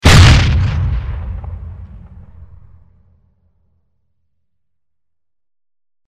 На этой странице собраны реалистичные звуки выстрелов из пушек разной мощности.
Выстрел из танковой пушки